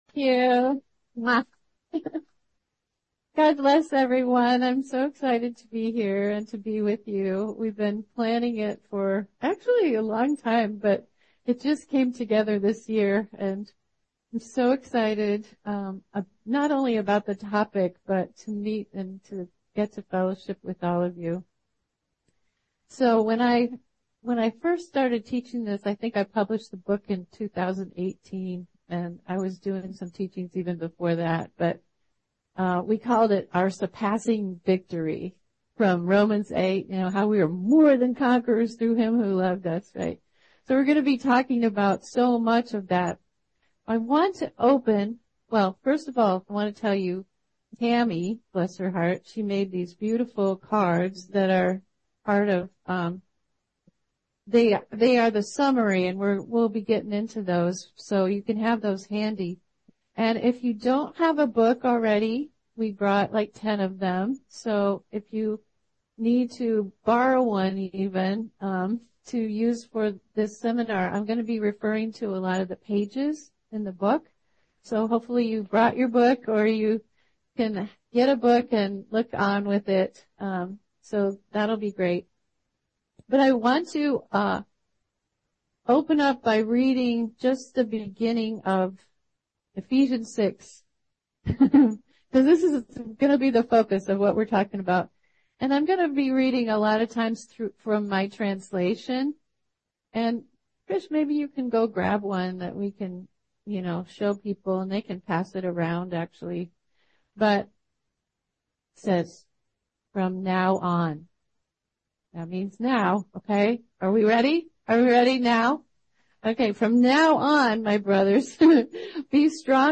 Armor of God Seminar 2024